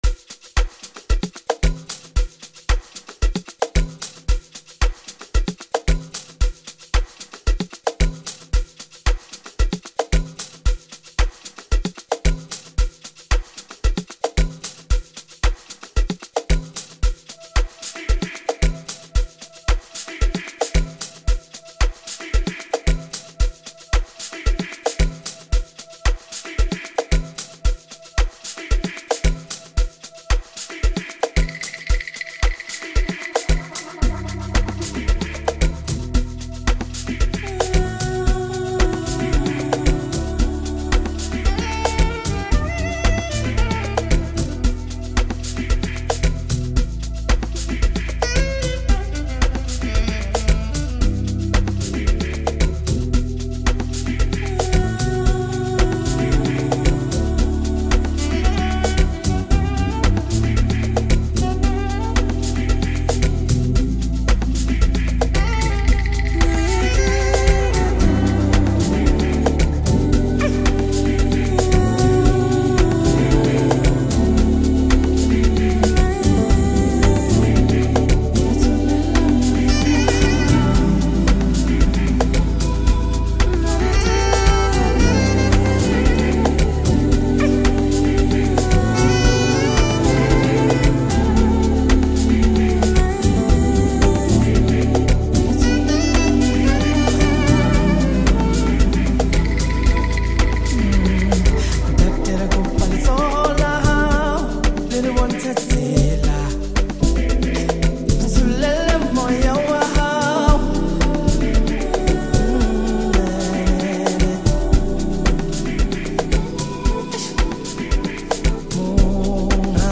if you’re into soulful vocals